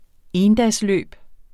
Udtale